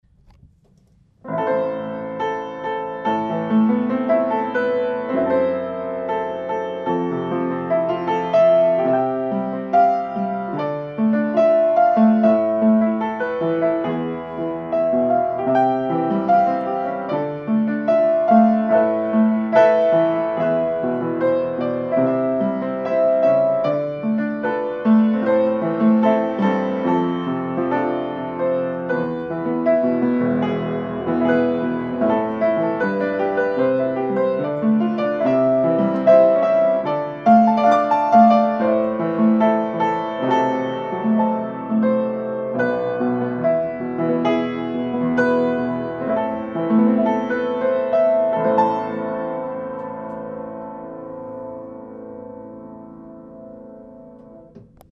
הראשון הוא עיבוד רגיל די קלאסי שבעז"ה אמור להיות בסרט של אחותי. העיבוד השני הוא עיבוד ג'אז שרובו אילתור ולכן זו התוצאה...